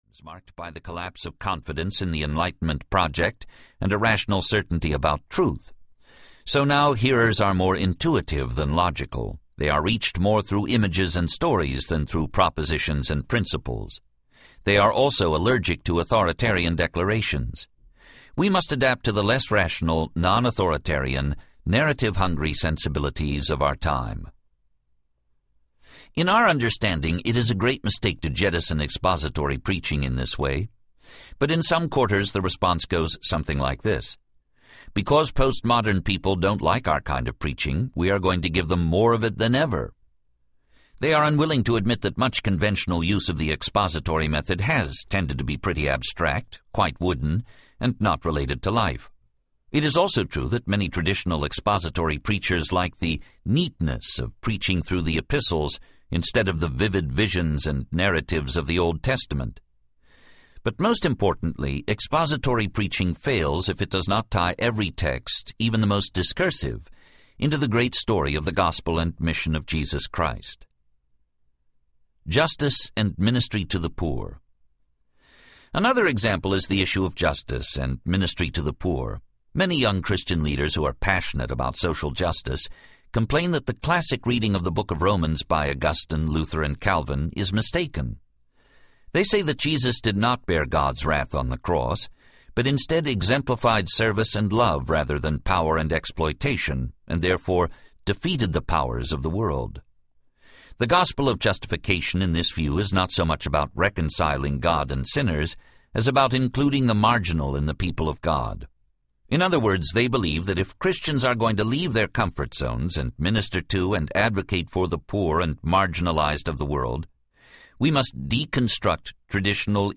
Gospel Centered Ministry Audiobook
Narrator
.47 Hrs. – Unabridged